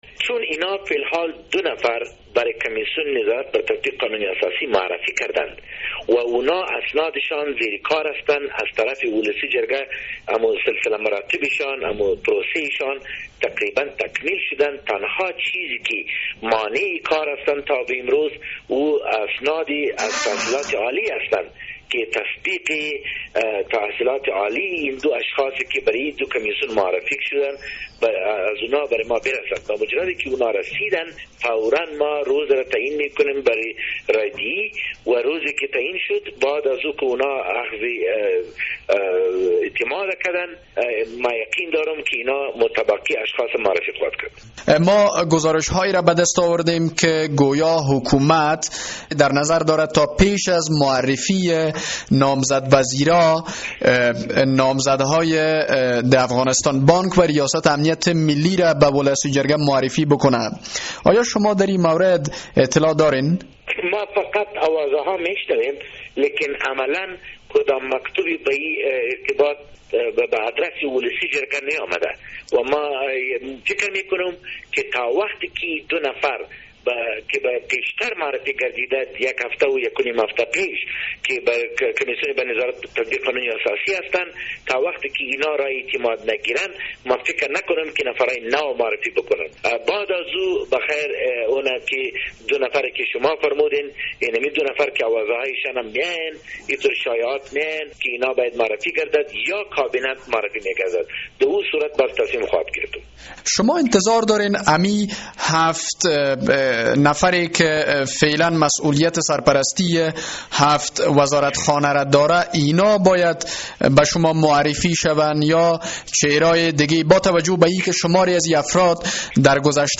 مصاحبه با خالد پشتون در مورد معرفی اعضای باقی ماندهء کابینه